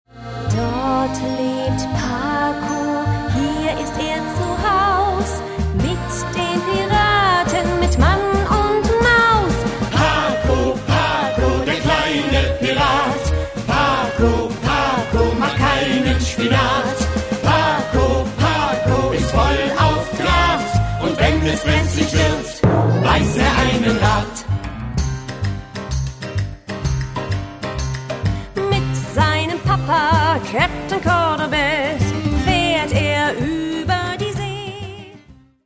Ein Hörspiel-Musical für Kinder